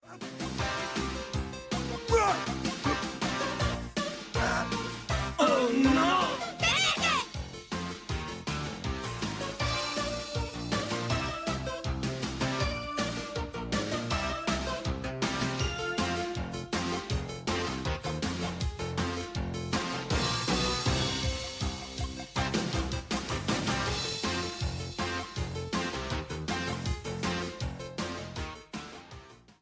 Music Sample